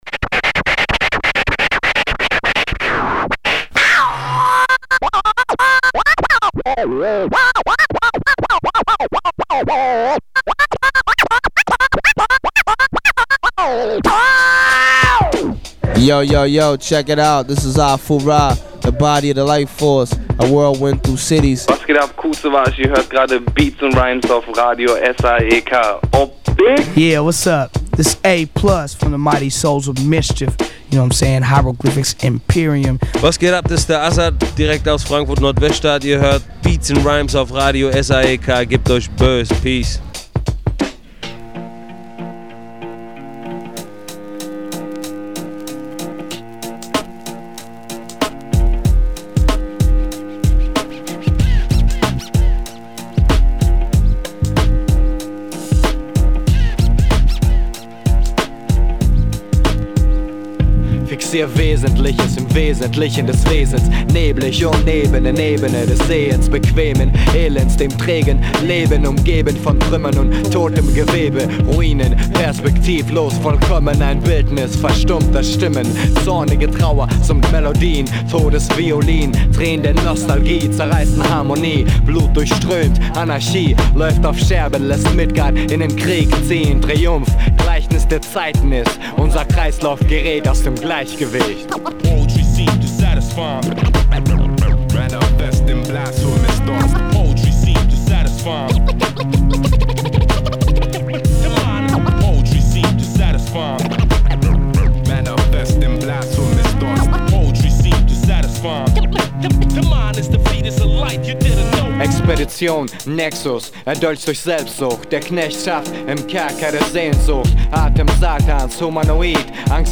Inhalte: Newz, VA Tips, Musik